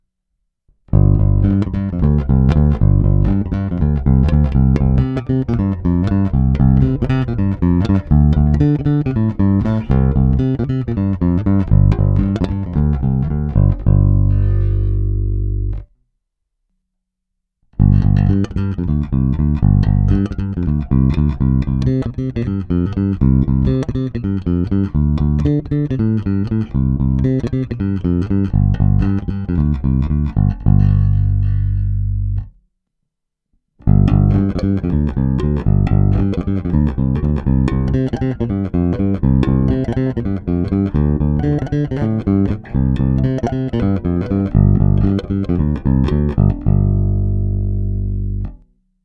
Není-li uvedeno jinak, následující nahrávky jsou provedeny rovnou do zvukové karty a s plně otevřenou tónovou clonou a s korekcemi na nule. Nahrávky jsou jen normalizovány, jinak ponechány bez úprav. Hráno nad použitým snímačem, v případě obou hráno mezi nimi. Na baskytaře jsou nataženy poniklované roundwound pětačtyřicítky Elixir Nanoweb v dobrém stavu.